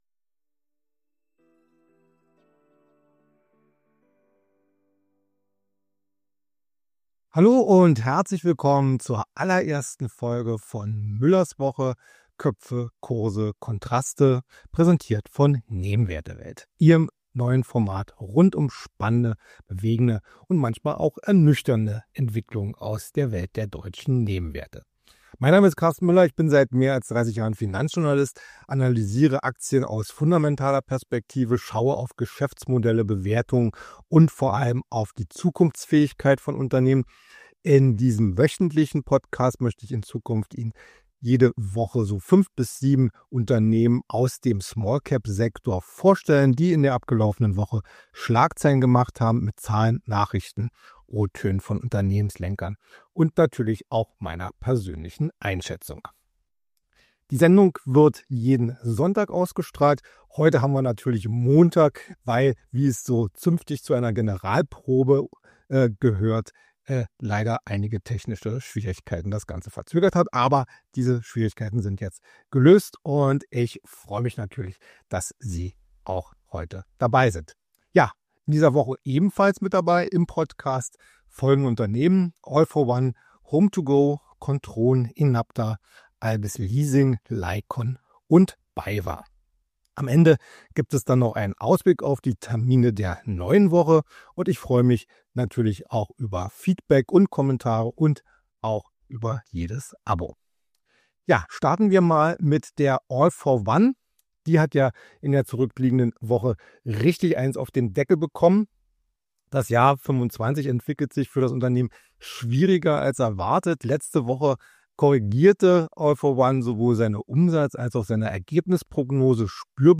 mit Einblicken, Einordnungen und O-Tönen von Unternehmenslenkern. Diesmal im Fokus: All for One, HomeToGo, Kontron, Enapter, Albis, Laiqon und Baywa.